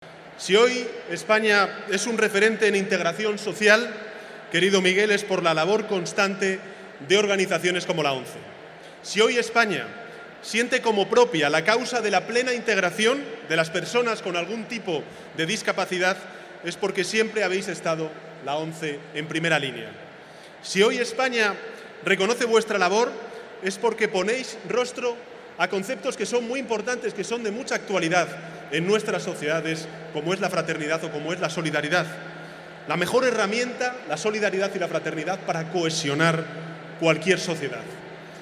Con esta rotundidad se manifestaba el presidente del Gobierno de España, Pedro Sánchez, en el acto central de celebración en Madrid del Día de Santa Lucía por parte de la ONCE, que este año conmemoraba además su 80 aniversario.